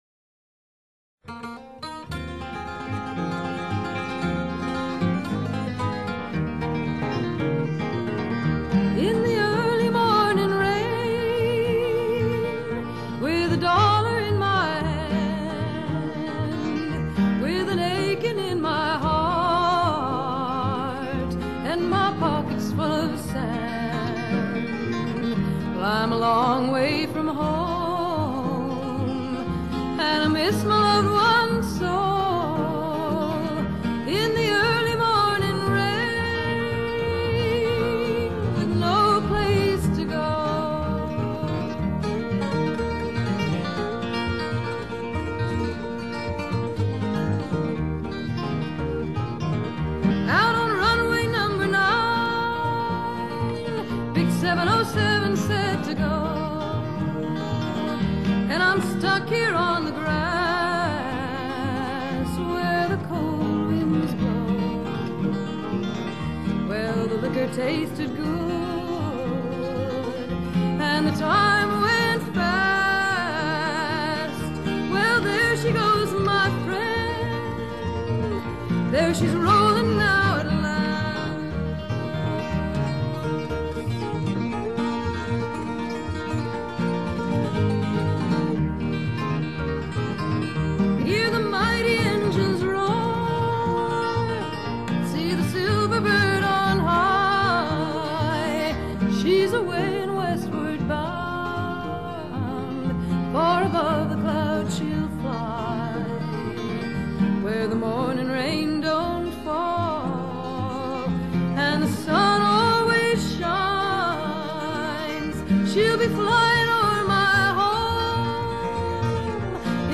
Folk/Folk-Rock | EAC Rip | Flac(Image) + Cue + Log |
她的嗓音清亮透明，充滿女性純真的柔美感性，每一首歌經過她的詮釋，都變得具有淨化心靈的神奇效果。